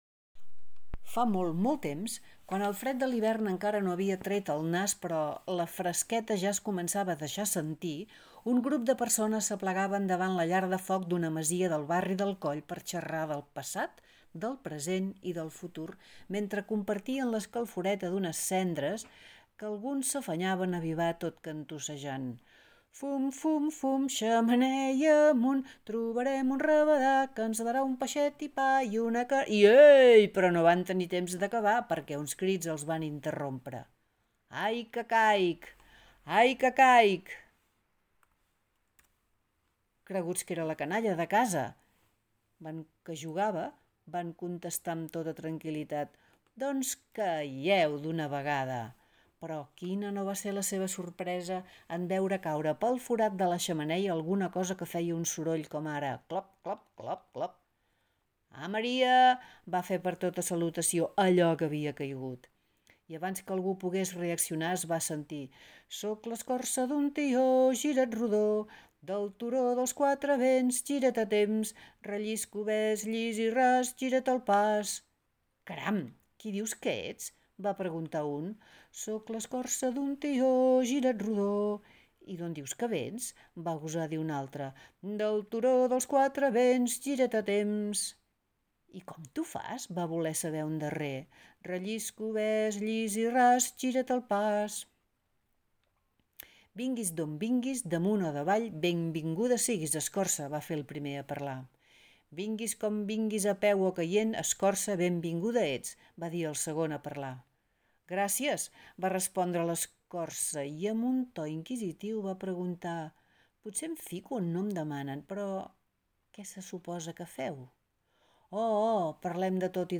Annexes Audioconte Conte Compartir Tweet